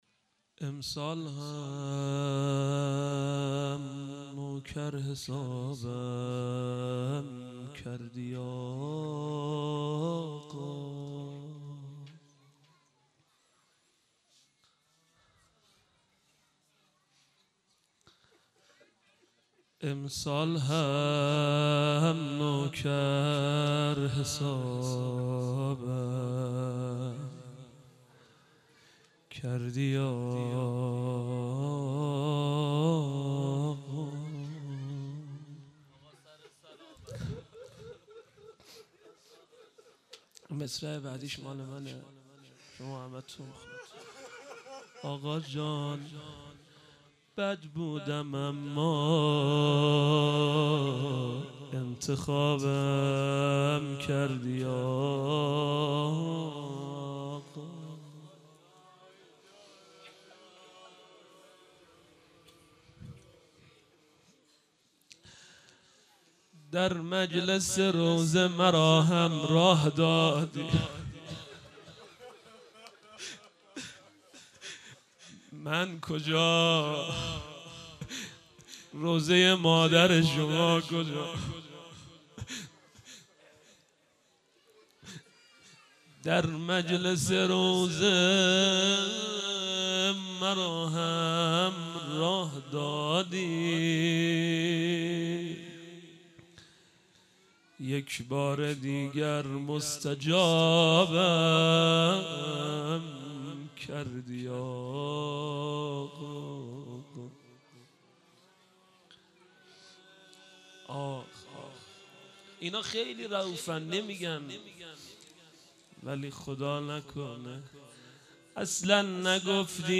مجمع دلسوختگان بقیع- شب چهارم- روضه